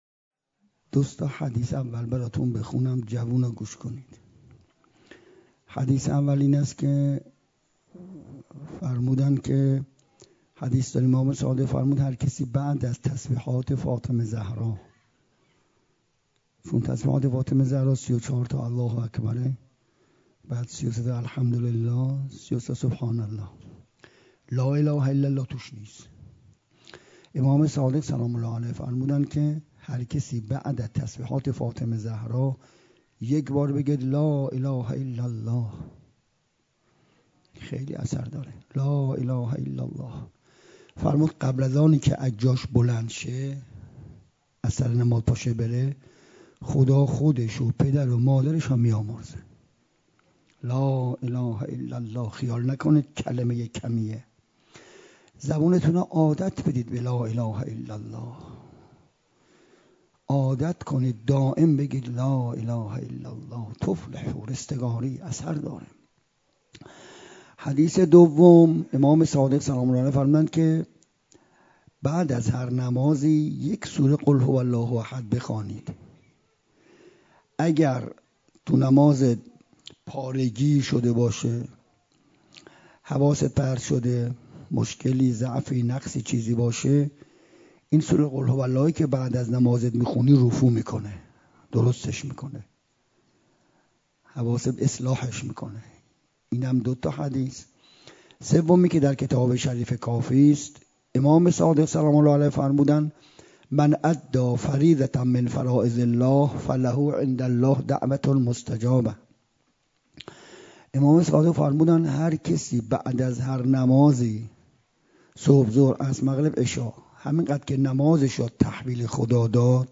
9 شهریور 97 - حسینیه بیت العباس - سخنرانی